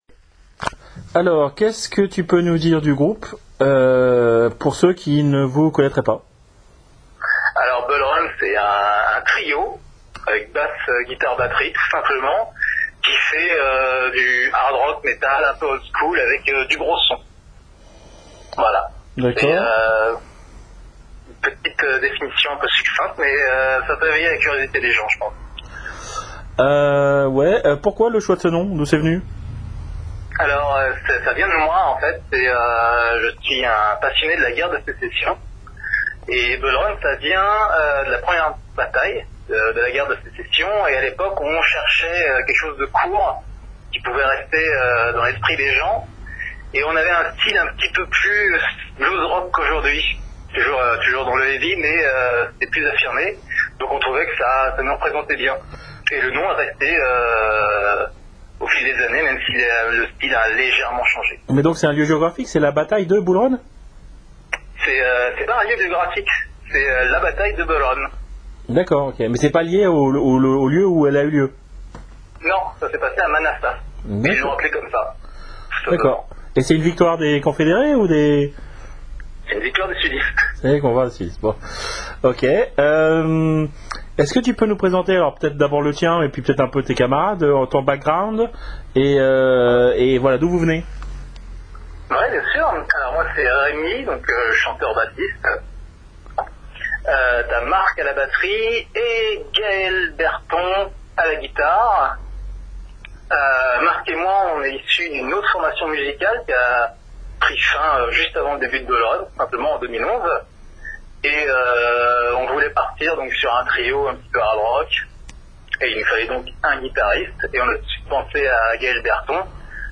BULLRUN (interview